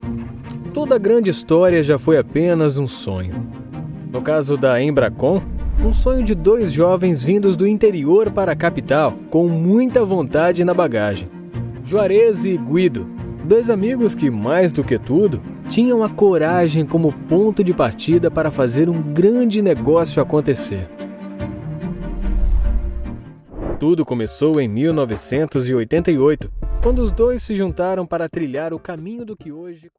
All voiceovers are recorded in an acoustic booth, resulting in clean audio free of any type of interference.
Sprechprobe: Industrie (Muttersprache):